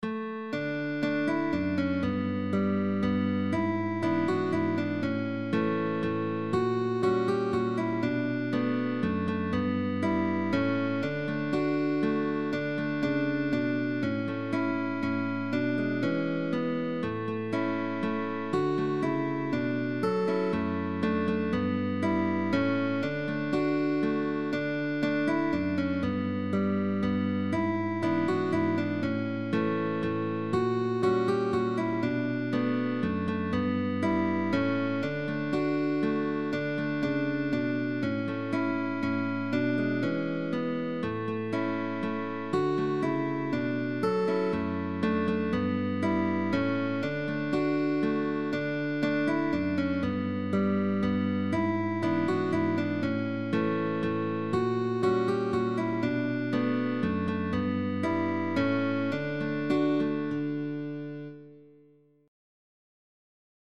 We wish you a merry Christmas by Guitar duo sheetmusic.
We wish you a merry christmas (English Carol) FREE
II With second guitar accompaniment for the teacher.